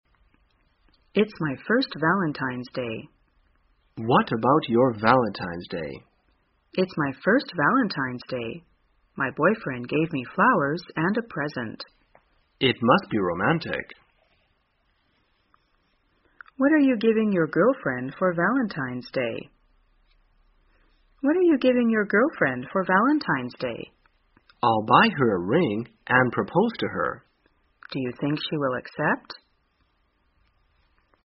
在线英语听力室生活口语天天说 第257期:怎样过情人节的听力文件下载,《生活口语天天说》栏目将日常生活中最常用到的口语句型进行收集和重点讲解。真人发音配字幕帮助英语爱好者们练习听力并进行口语跟读。